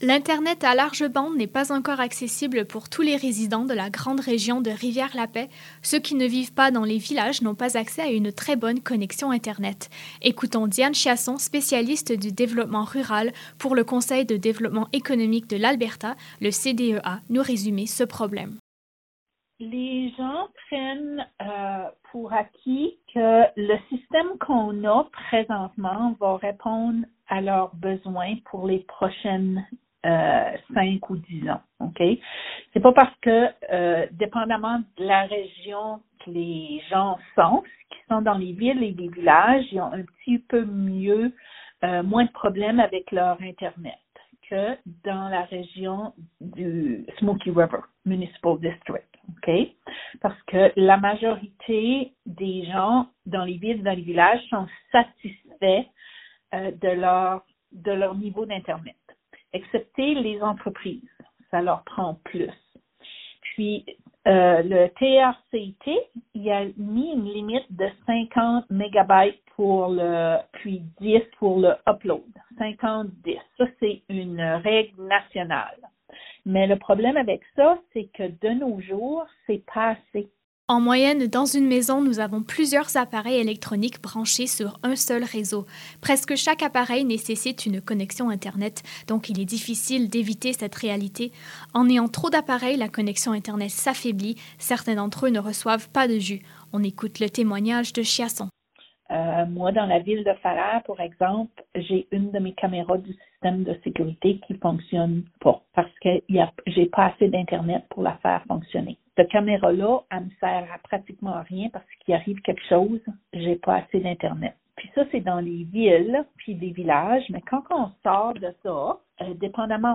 Reportage-internet-broadband.mp3